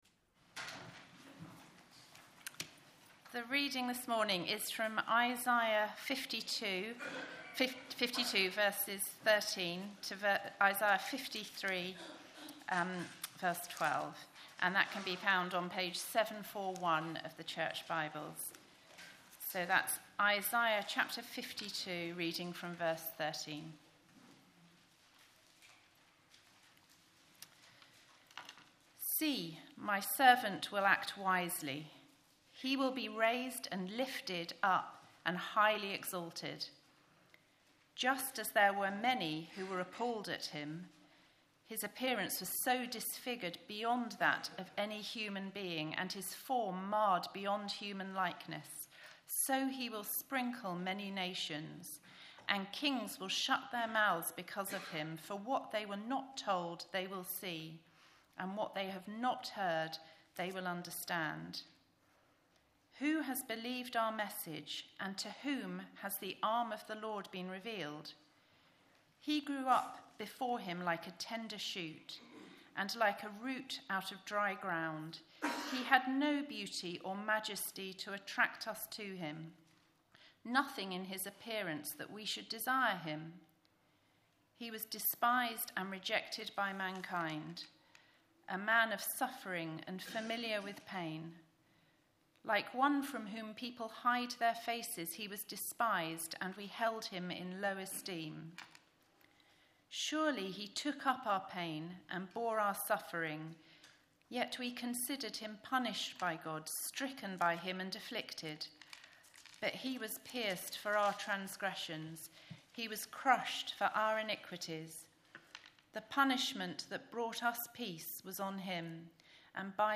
Sermons | ChristChurch Banstead